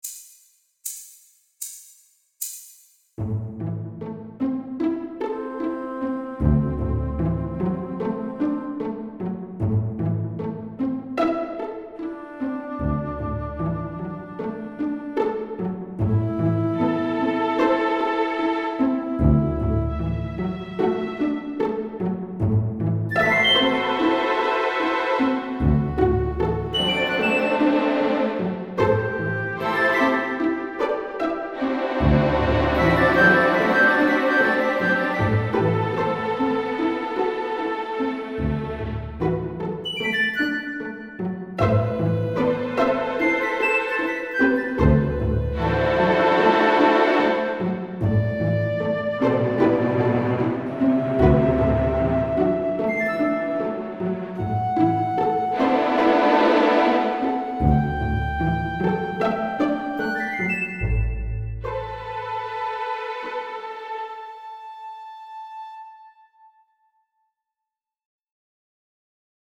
Tonalidade: la dórico; Compás 4/4
acompanamento_dorico.mp3